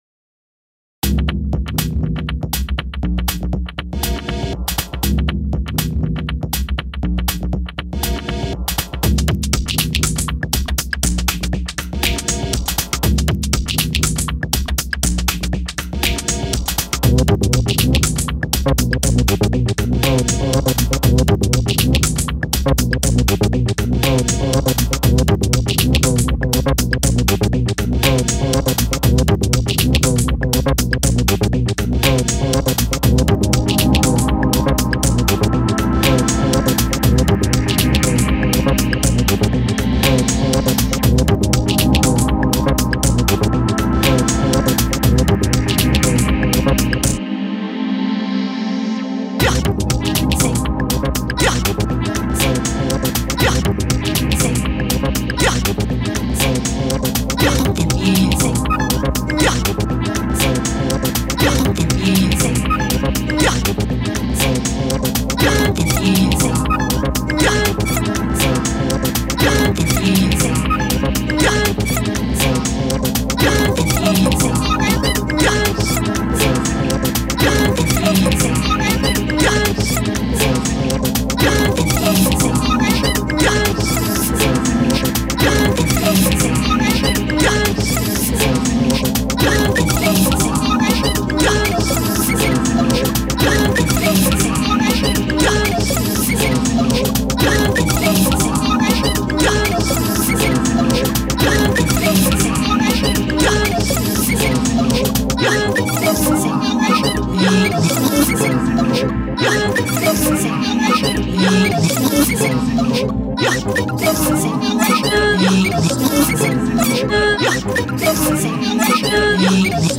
Grooved electronica.